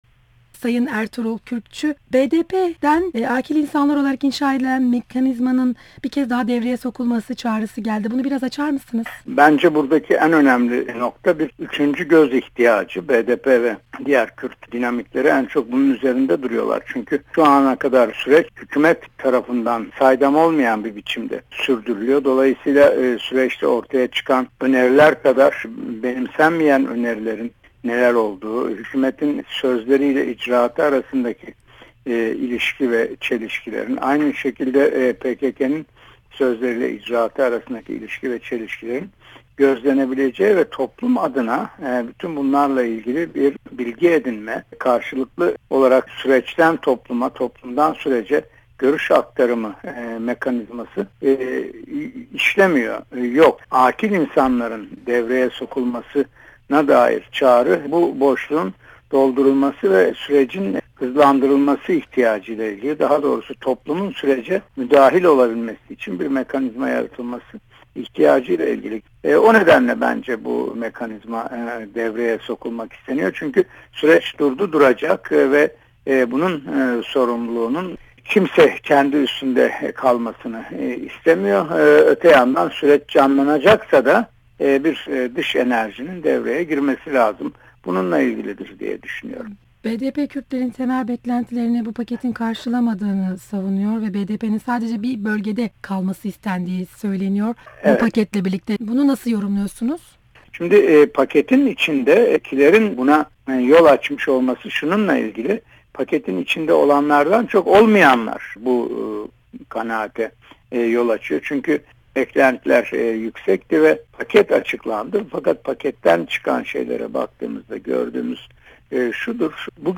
Ertuğrul Kürkçü ile Söyleşi